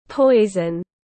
Poison /ˈpɔɪ.zən/